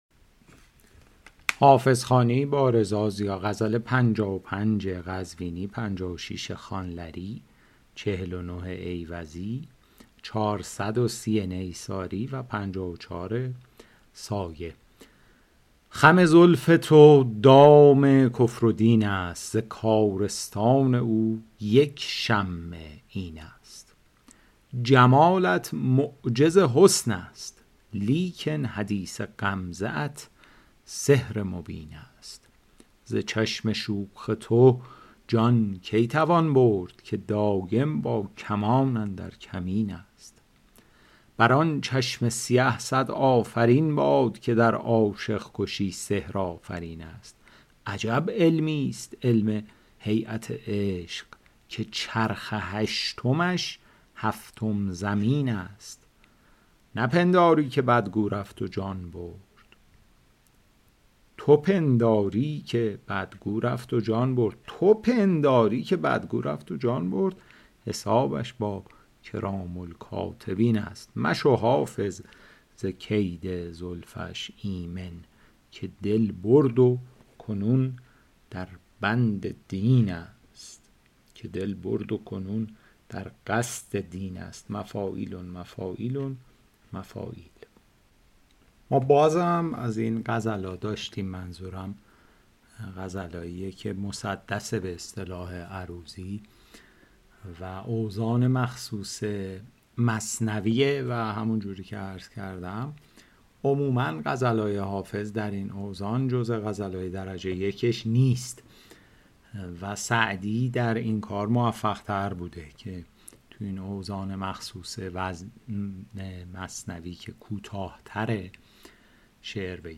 شرح صوتی غزل شمارهٔ ۵۵